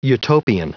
Prononciation du mot utopian en anglais (fichier audio)
Prononciation du mot : utopian